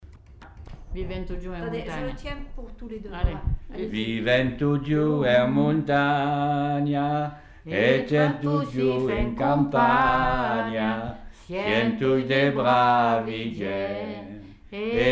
Aire culturelle : Provence
Lieu : Coaraze
Genre : chant
Effectif : 2
Type de voix : voix d'homme ; voix de femme
Production du son : chanté
Classification : chanson identitaire
Notes consultables : Qualité technique très mauvaise. Problème d'enregistrement.